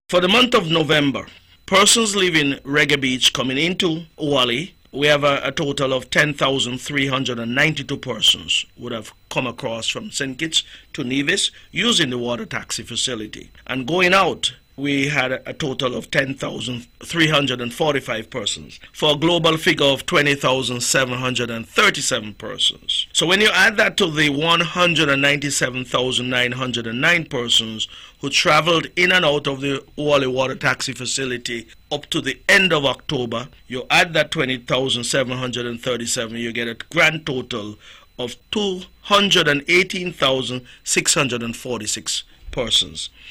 During the ‘On Point’ Programme on Friday, December 8th, Host of the Programme and Special Advisor to the Premier, Hon. Alexis Jeffers spoke on the usage of the Oualie Water Taxi Facility on Nevis.